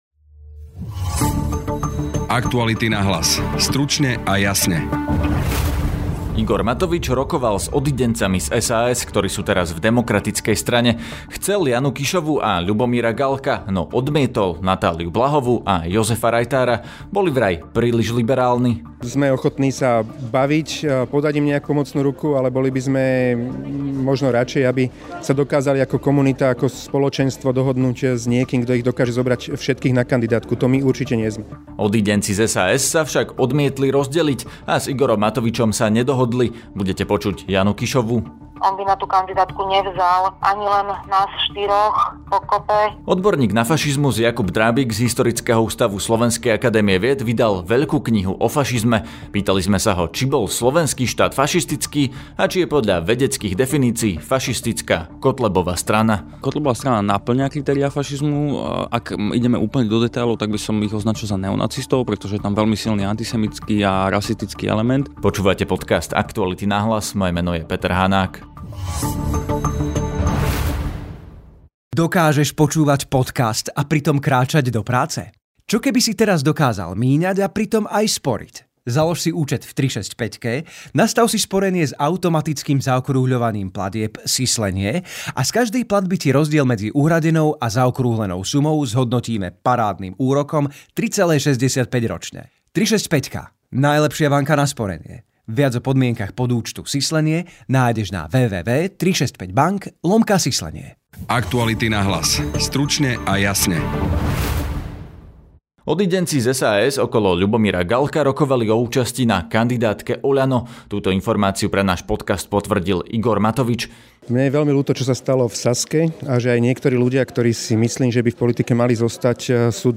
Historik fašizmu: Tiso nebol fašista, Kotleba jednoznačne je (rozhovor)